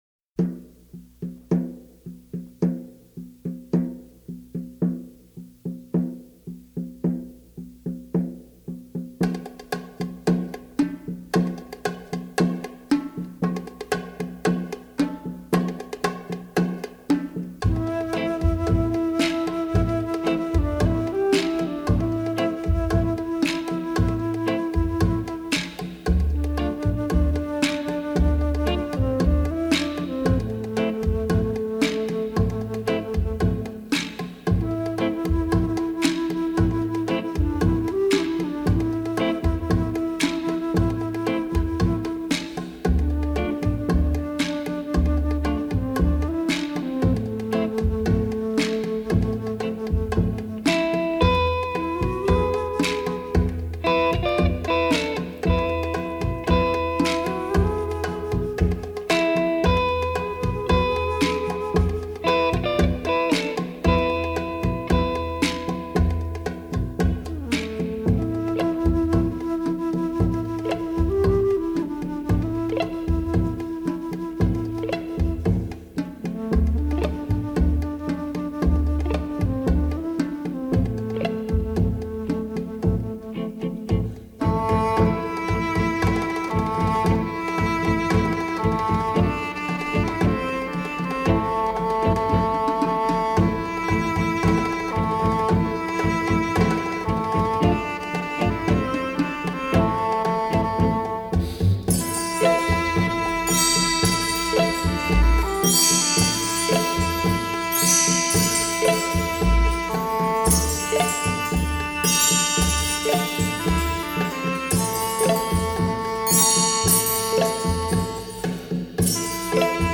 ★傑出鼓手領軍演奏，50 年代流行的時尚沙發音樂，左右聲道穿插衝擊聽覺神經的發燒逸品！
★變化無窮的打擊節拍，迷幻撩人的電吉他聲響，各式樂器的巧妙組合，超越時代的立體天碟！